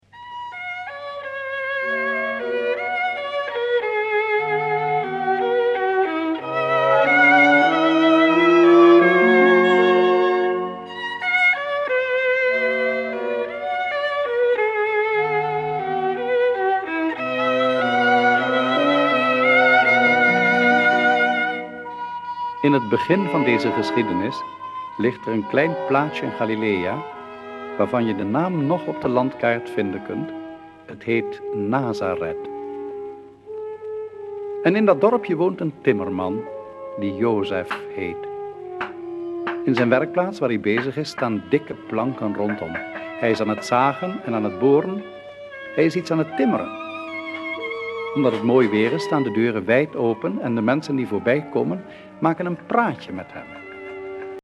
Hoorspel met muziek
25 cm LP | 33 toeren
Een hoorspel door diverse acteurs